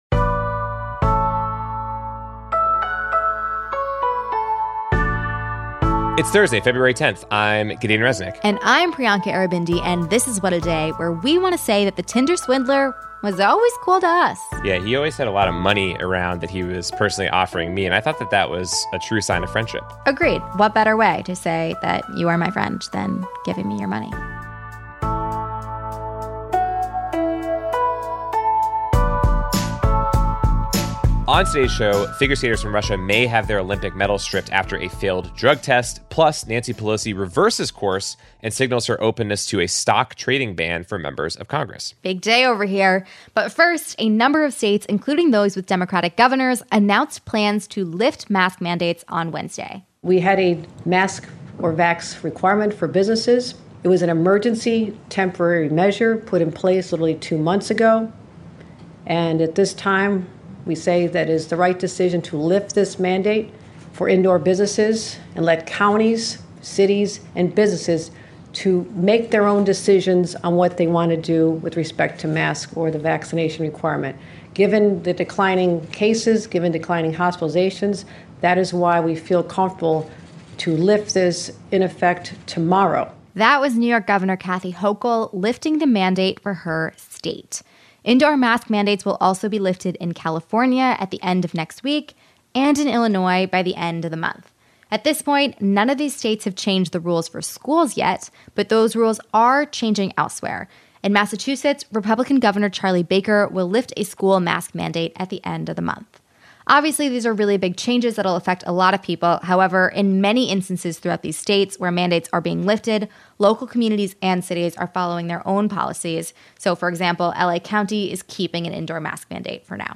Several states announced plans to lift mask mandates this week, but the federal government is taking a more cautious approach, and has communicated that hospitalizations and deaths are still high enough that relaxing guidelines at this point would be premature. Dr. Céline Gounder, a clinical assistant professor of Medicine and Infectious Diseases at NYU who has been in touch with the White House, joins us to discuss what comes next in this new, slightly less-masked phase of the pandemic.